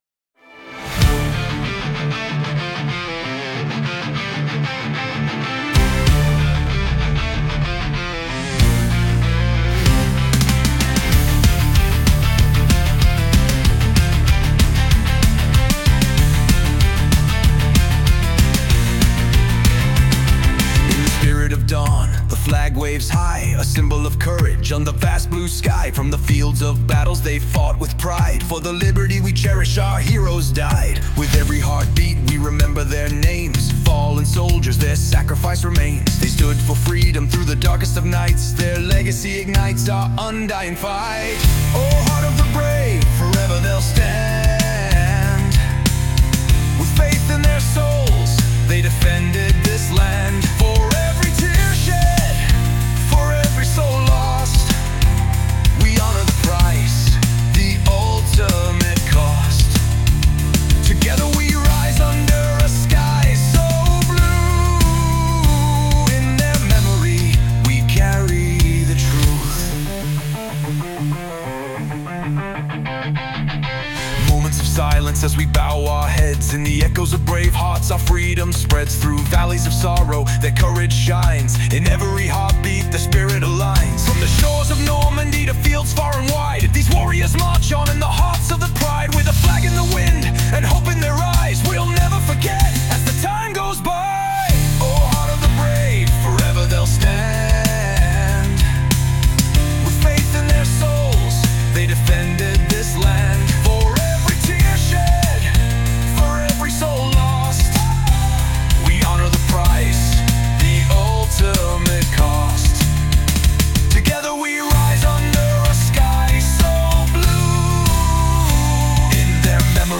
Patriotic Music